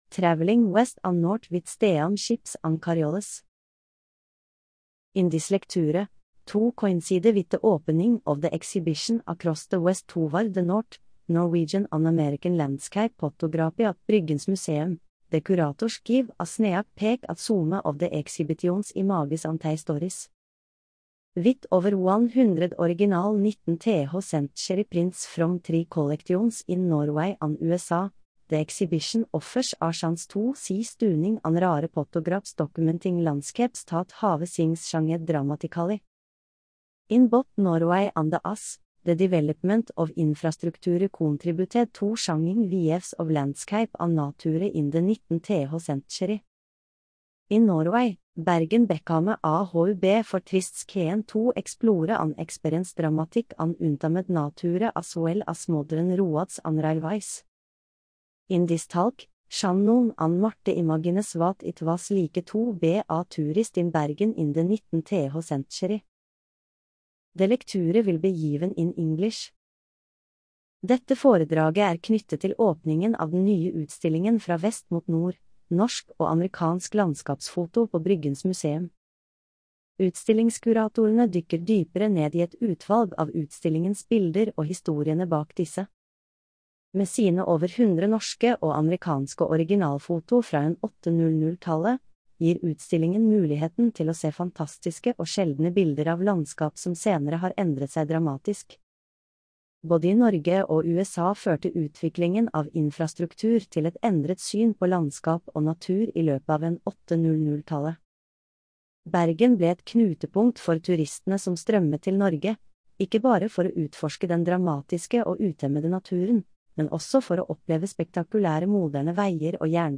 Mandagsforedrag